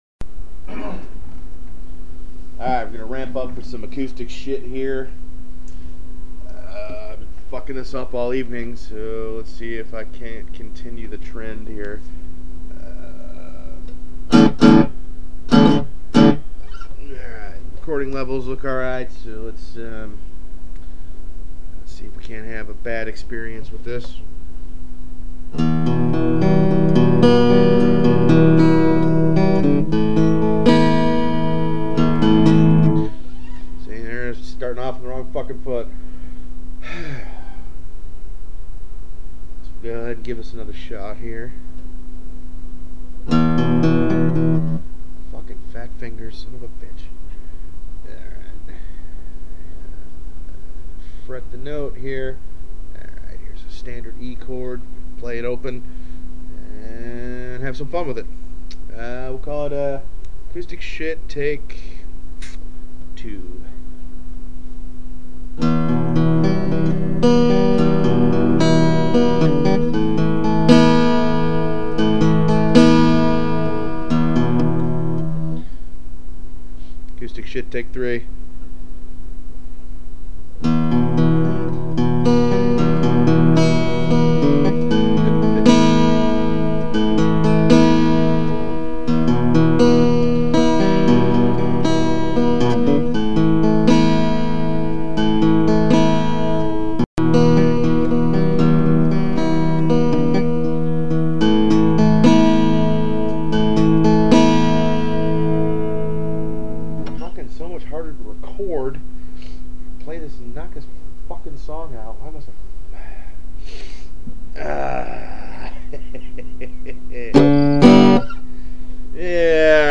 Amusing drunken acoustic recording mess:
- Recorded via external mic directly into my PC with Adobe Audition.